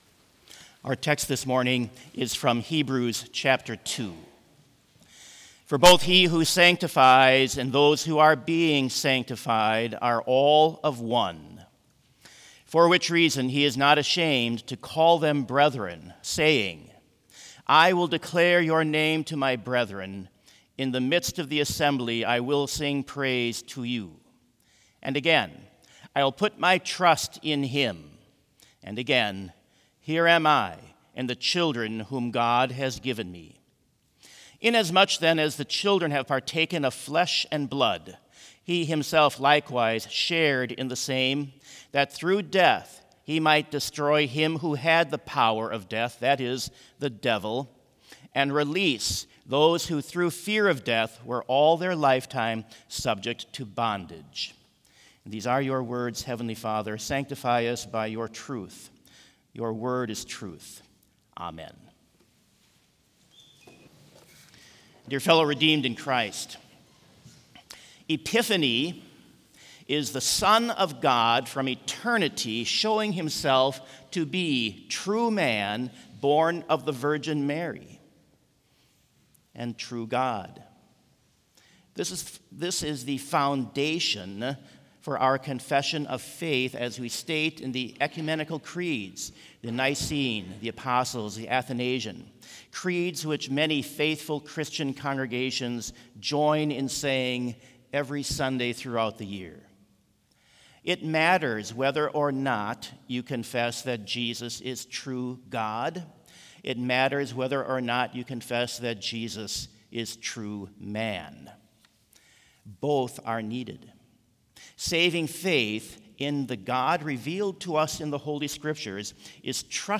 Complete Service
• Hymn 166 - Arise and Shine in Splendor View
• Devotion
This Chapel Service was held in Trinity Chapel at Bethany Lutheran College on Wednesday, January 7, 2026, at 10 a.m. Page and hymn numbers are from the Evangelical Lutheran Hymnary.